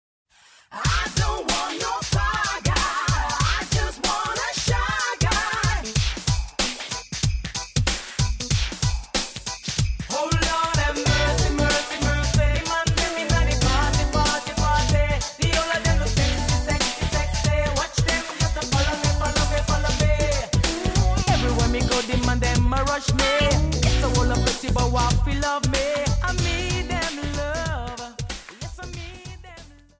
Foxtrott